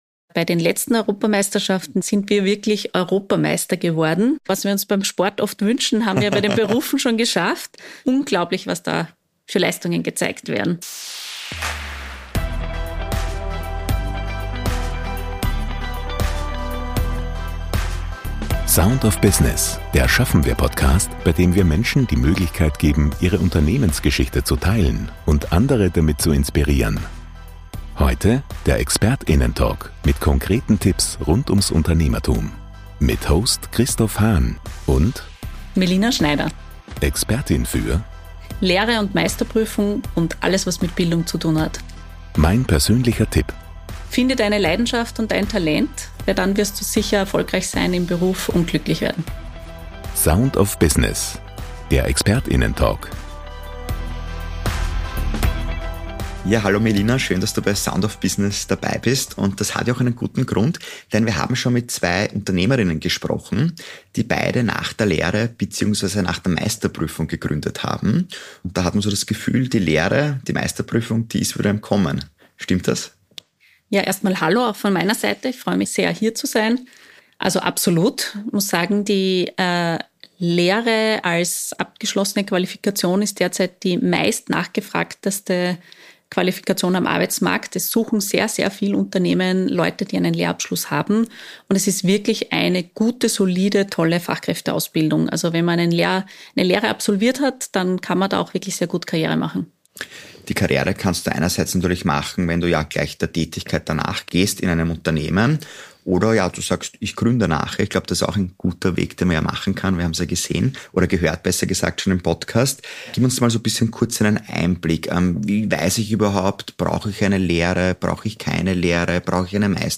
Heute im Gespräch: